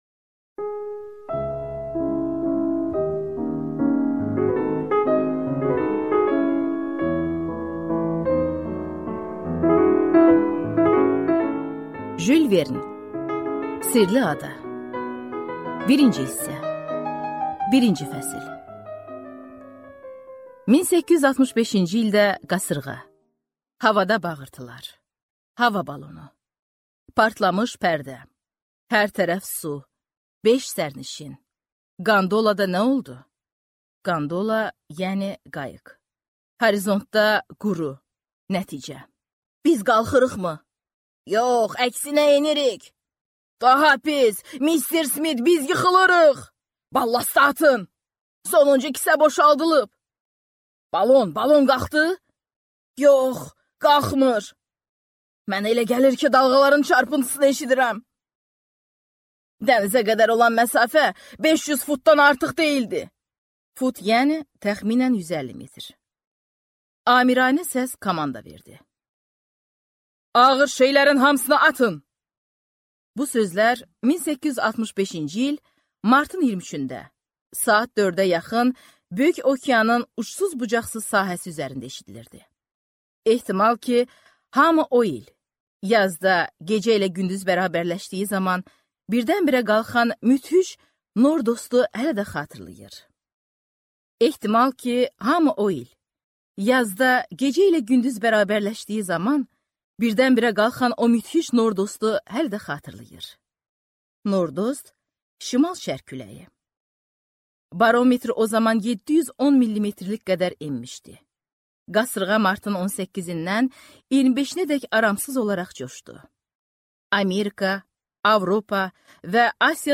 Аудиокнига Sirli ada | Библиотека аудиокниг